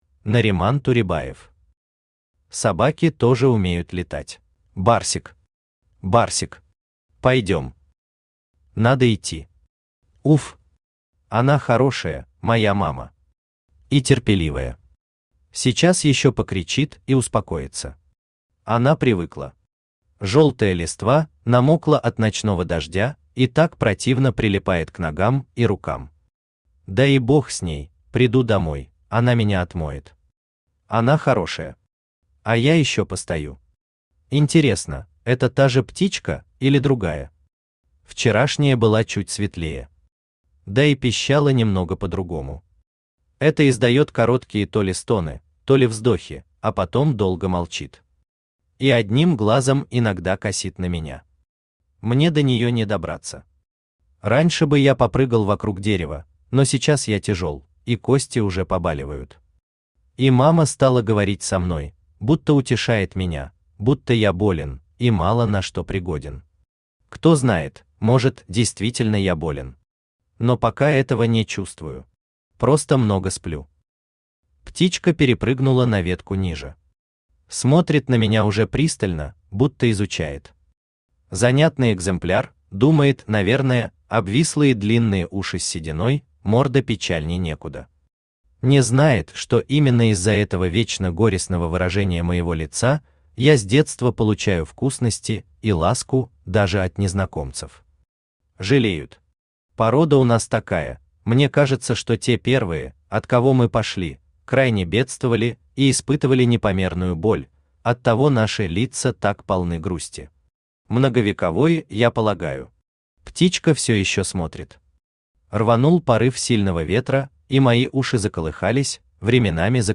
Аудиокнига Собаки тоже умеют летать | Библиотека аудиокниг
Aудиокнига Собаки тоже умеют летать Автор Нариман Туребаев Читает аудиокнигу Авточтец ЛитРес.